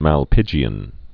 (măl-pĭgē-ən)